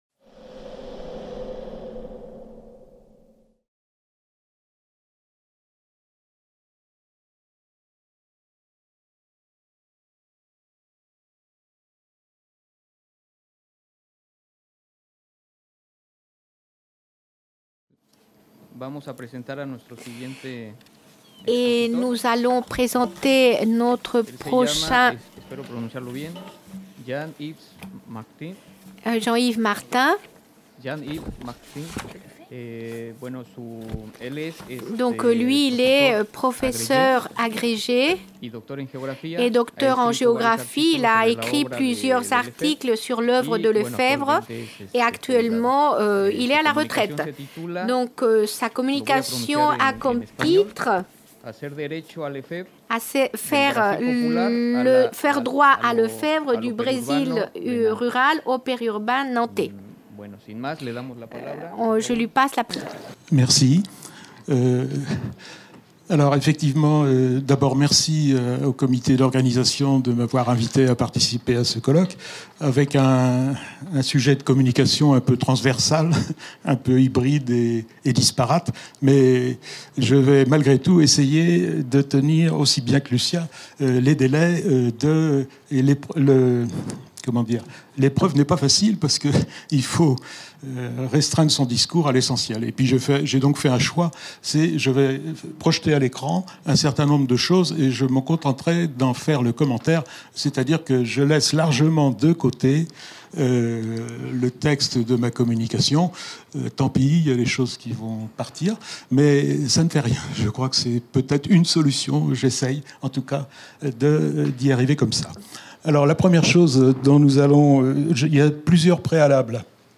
Cette communication a été filmée lors du colloque international "Le droit à Lefebvre" qui s'est déroulé du 29 au 31 mai 2018 à Caen. En France, l’œuvre d’Henri Lefebvre n’a pas eu le plus grand des succès, contrairement au continent Américain où ses travaux ont fortement résonnés.